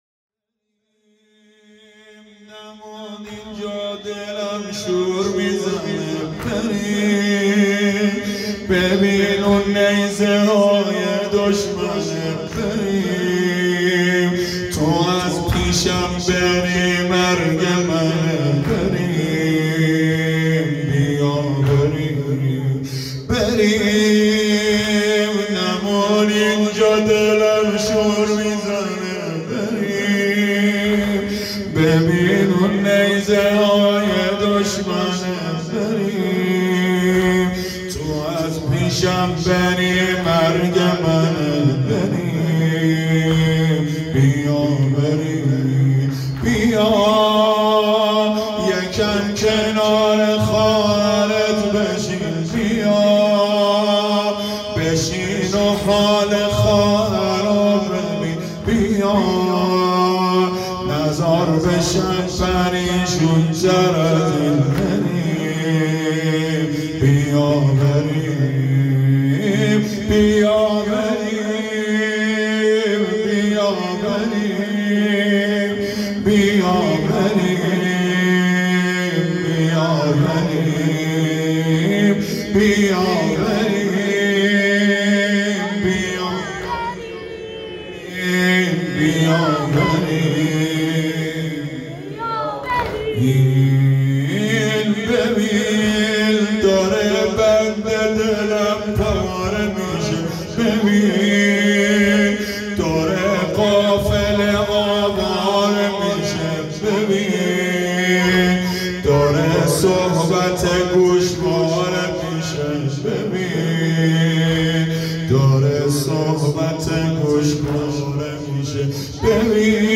واحد بریم نمون اینجا شب دوم محرم مسجد قمر بنی هاشم ۹۸